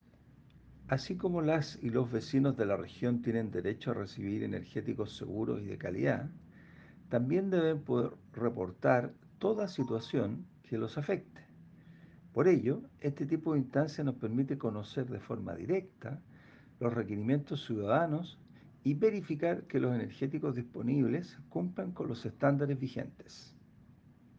Audio: Ricardo Miranda, Director Regional SEC O’Higgins